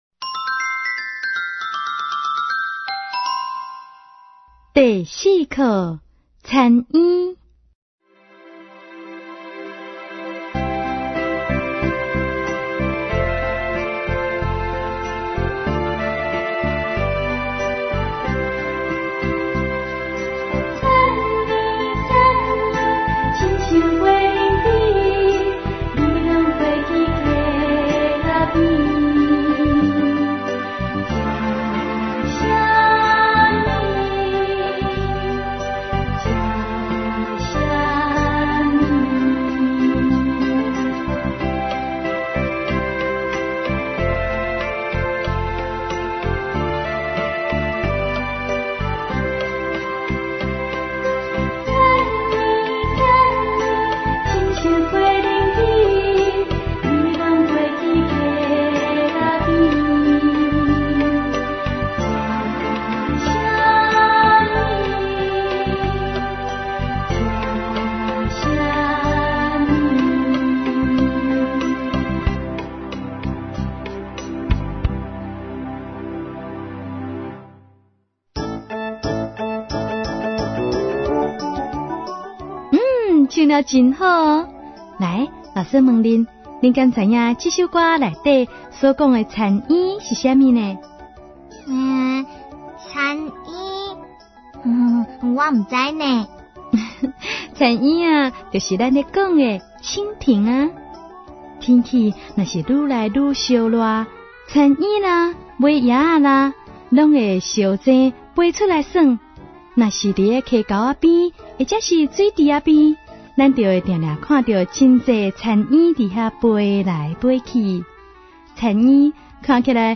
● 兒歌唱遊、常用語詞、短句對話 ●